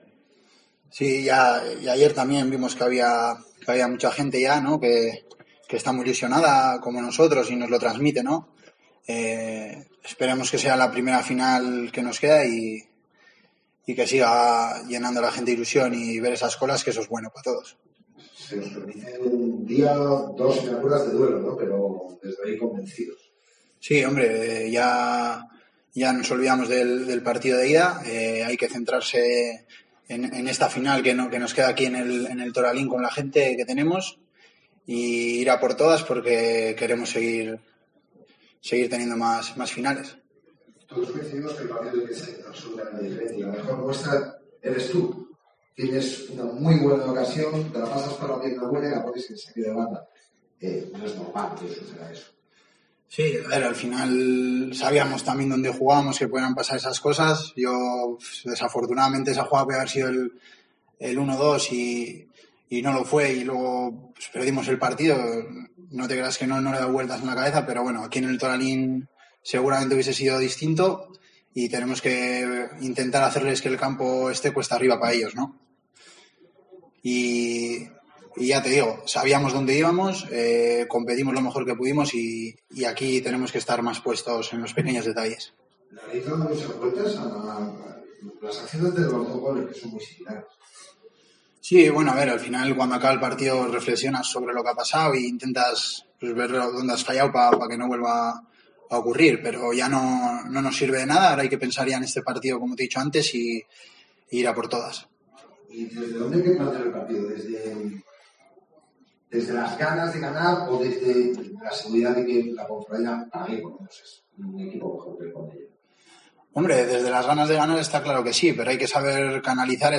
Escucha aquí las palabras del centrocampista de la Deportiva Ponferradina